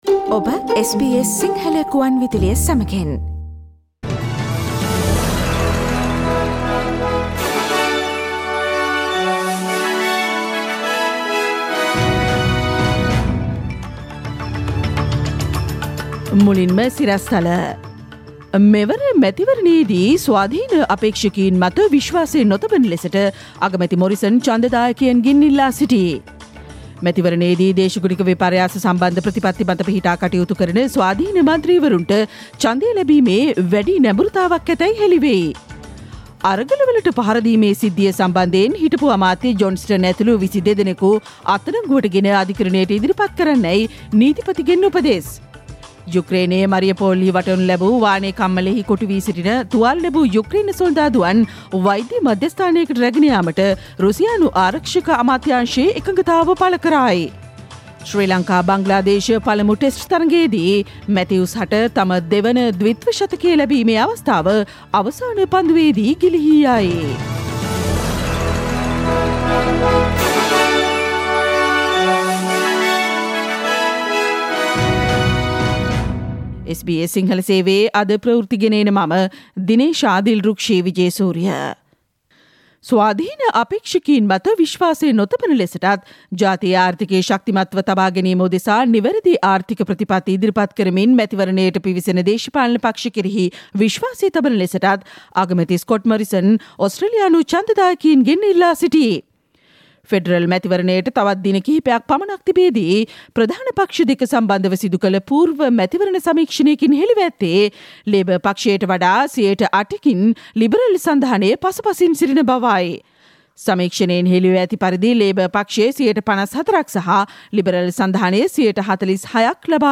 ඉහත චායාරූපය මත ඇති speaker සලකුණ මත ක්ලික් කොට මැයි 17 වන අඟහරුවාදා SBS සිංහල ගුවන්විදුලි වැඩසටහනේ ප්‍රවෘත්ති ප්‍රකාශයට ඔබට සවන්දිය හැකියි.